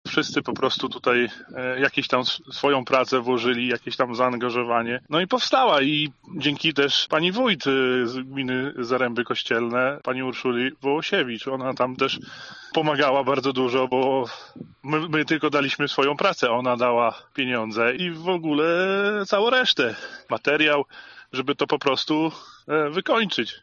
O powstaniu inwestycji mówi sołtys Pętkowa Wielkiego, Łukasz Rostkowski: